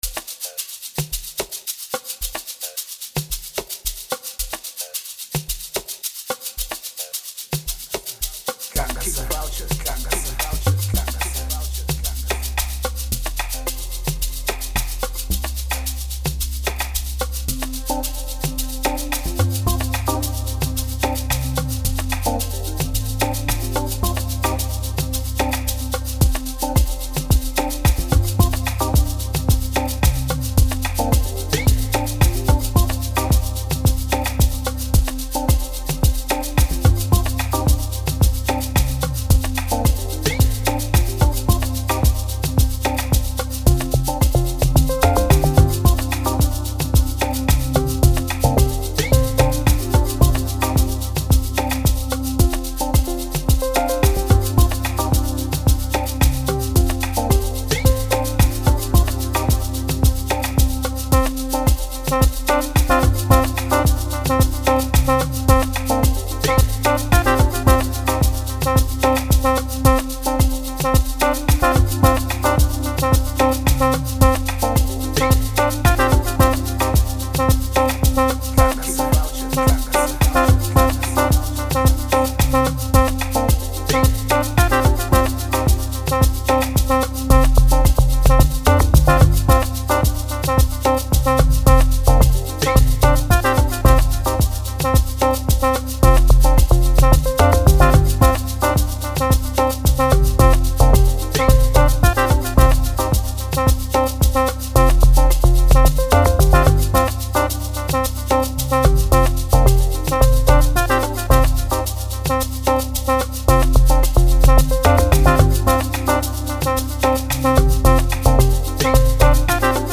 05:44 Genre : Amapiano Size